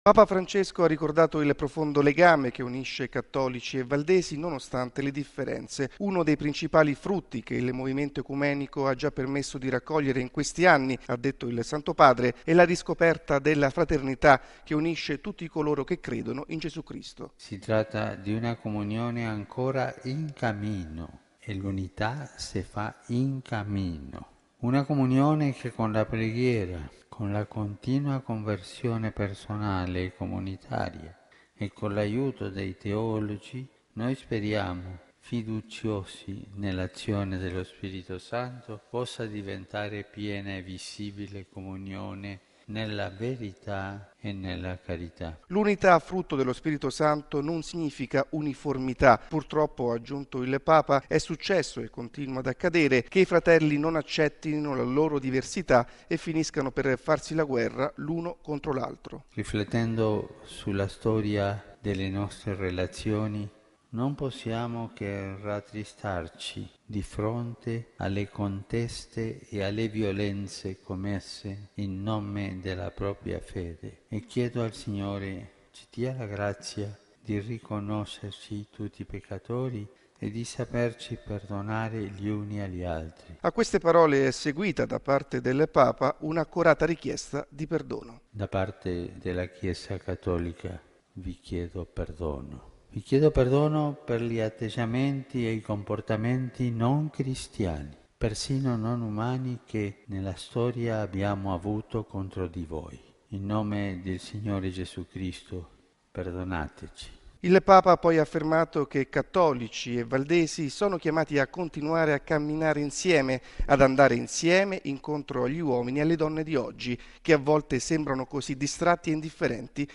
Uno storico incontro, la visita al Tempio valdese, ha aperto la seconda giornata della visita pastorale di Papa Francesco a Torino.